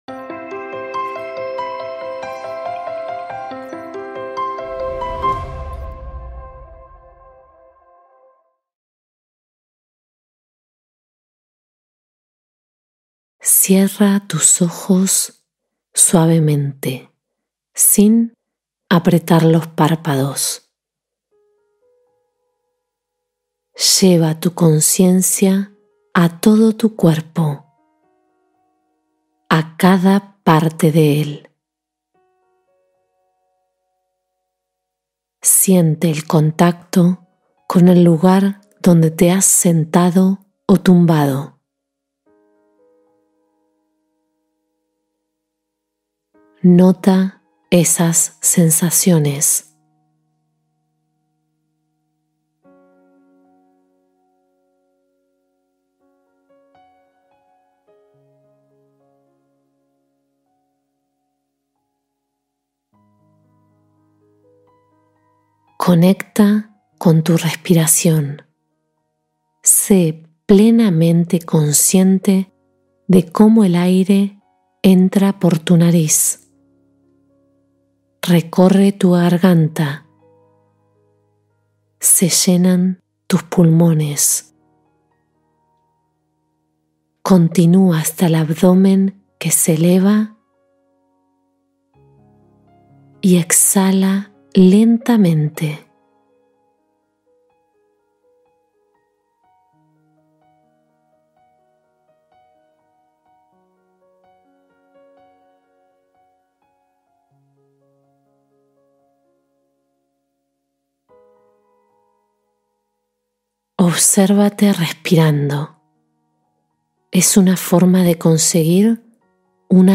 Relajación Profunda Guiada: Meditación para Calmar Cuerpo y Mente